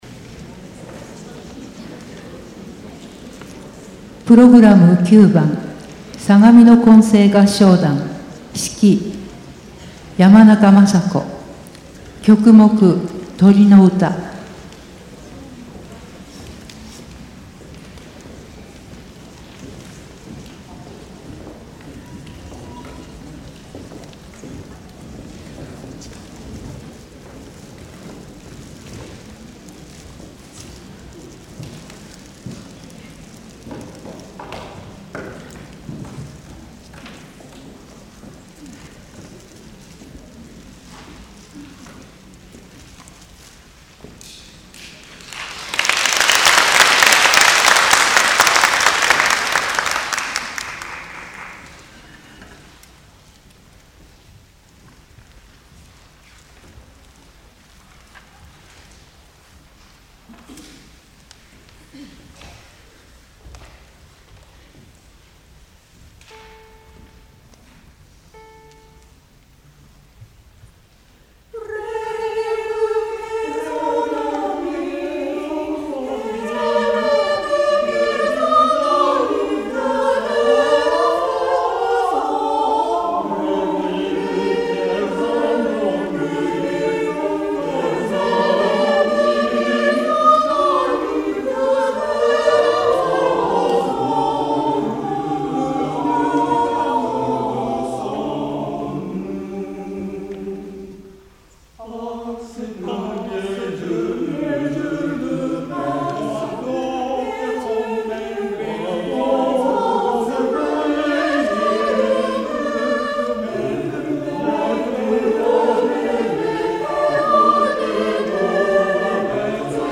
神奈川県相模原市で、アカペラのアンサンブルを楽しんでいます。
中世・ルネッサンスのポリフォニーの曲を中心に歌っています。
相模原市のミニ合唱祭”かがやけ歌声”に出演しました
(杜のホールはしもと)